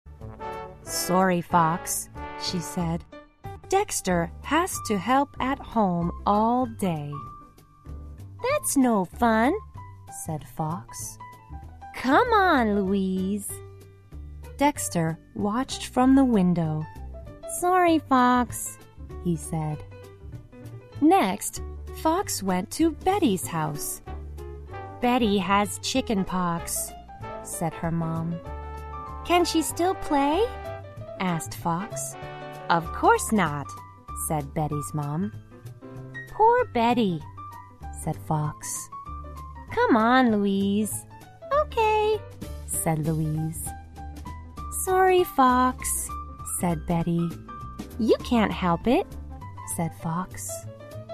在线英语听力室小狐外传 第2期:对不起小狐的听力文件下载,《小狐外传》是双语有声读物下面的子栏目，非常适合英语学习爱好者进行细心品读。故事内容讲述了一个小男生在学校、家庭里的各种角色转换以及生活中的趣事。